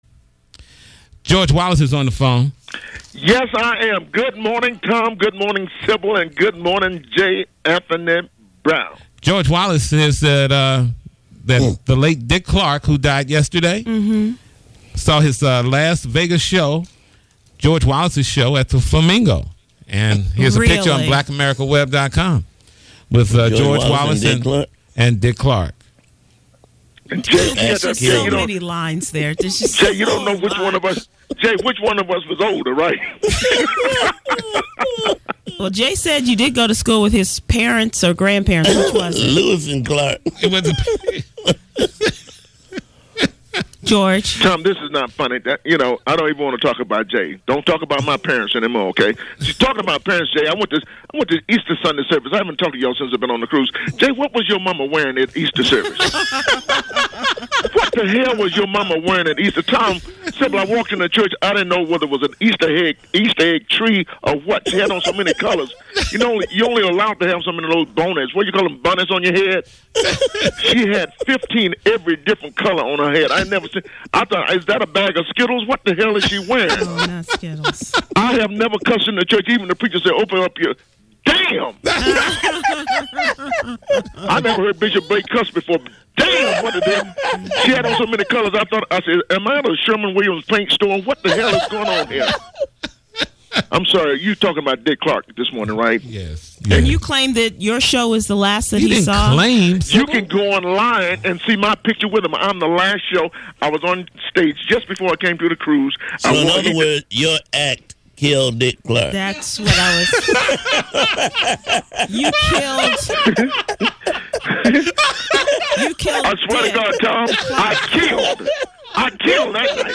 As the Tom Joyner Morning Show crew reminisced on the icon’s career, George Wallace had to have his say, too.
And of course he couldn’t do any of it without a few extra laughs along the way.